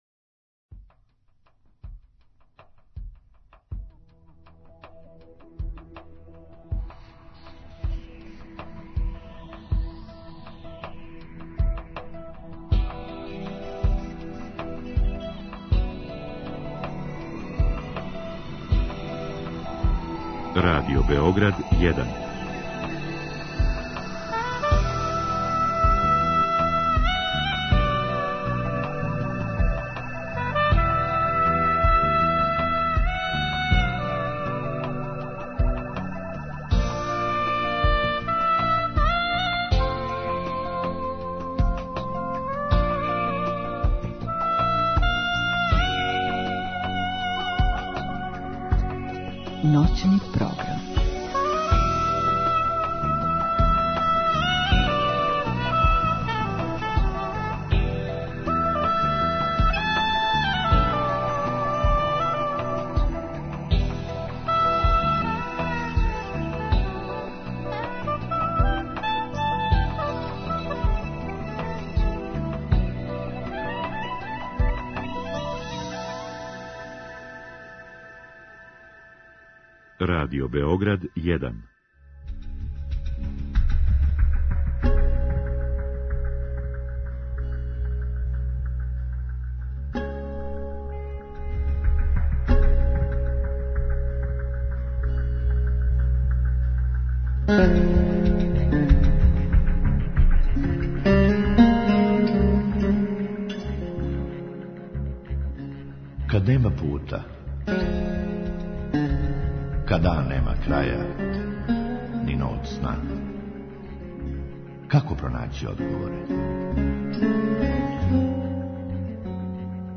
У другом сату гошћи постављамо питања слушалаца која су стигла путем наше Инстаграм странице.